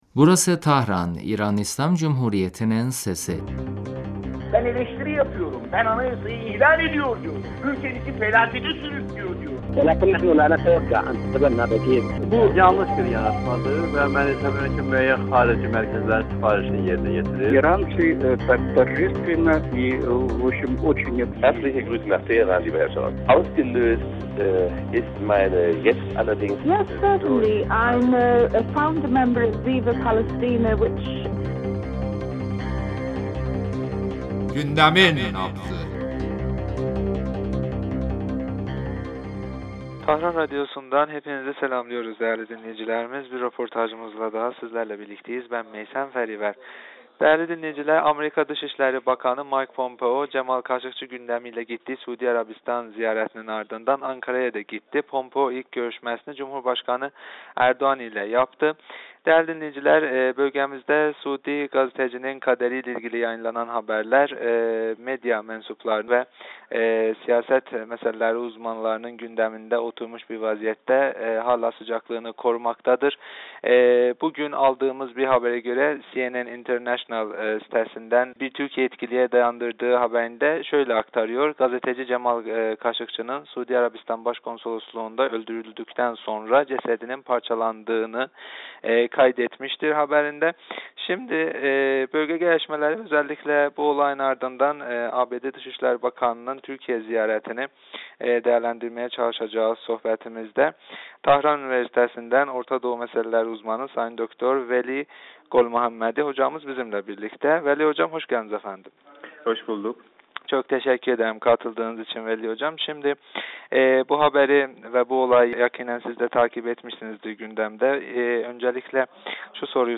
radyomuza verdiği demecinde